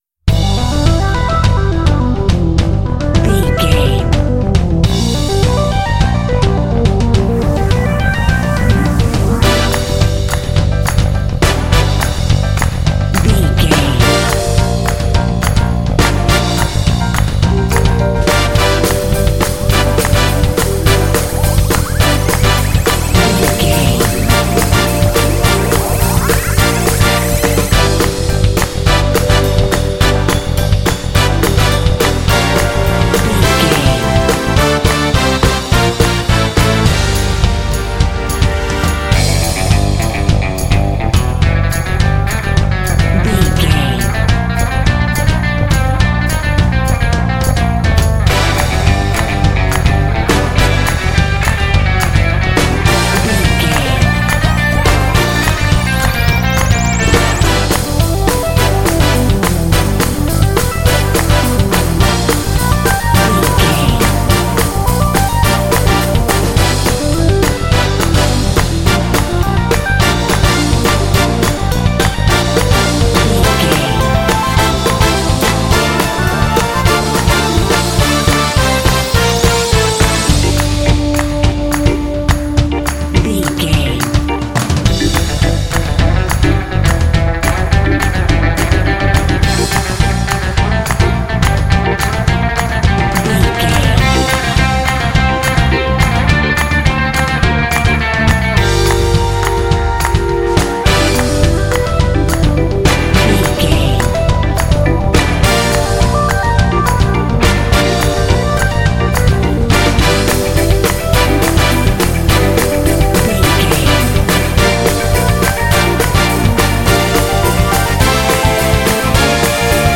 Fast paced
Aeolian/Minor
Fast
energetic
dark
groovy
funky
synthesiser
drums
bass guitar
brass
electric guitar
synth-pop
new wave